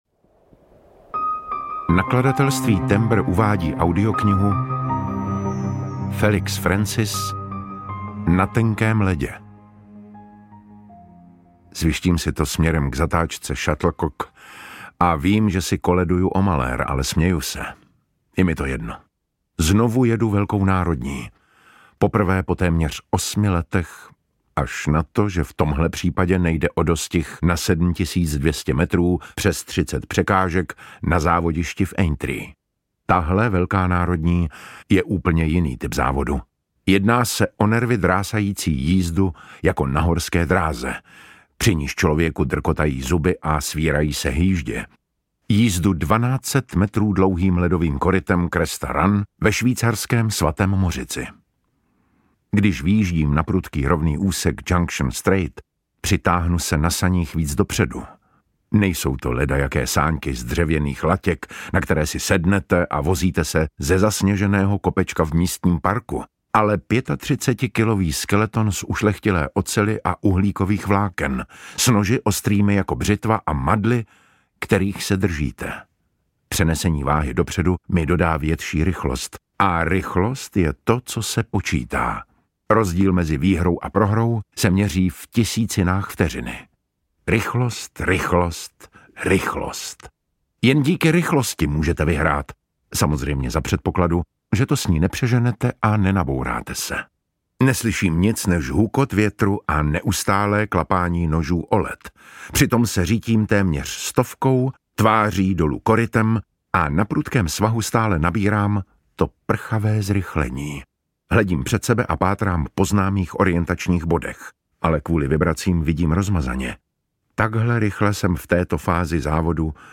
Na tenkém ledě audiokniha
Ukázka z knihy
• InterpretDavid Matásek